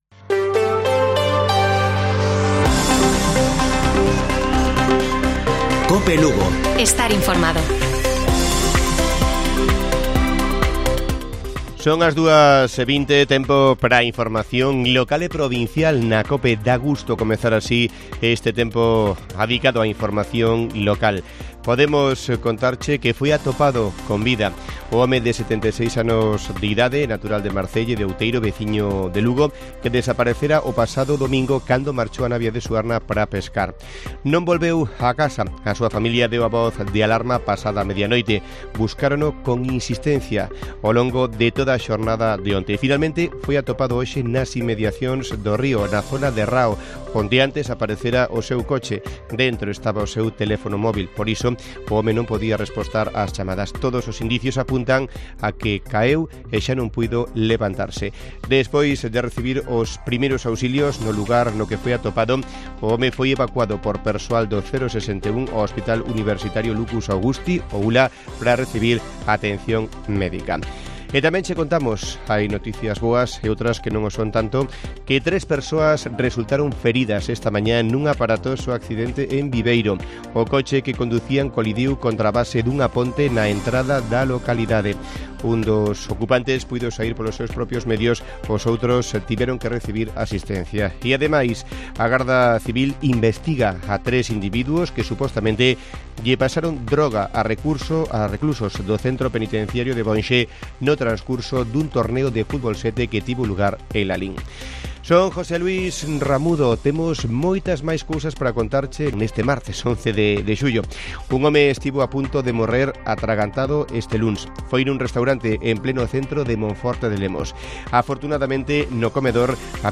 Informativo Mediodía de Cope Lugo. 11 DE JULIO. 14:20 horas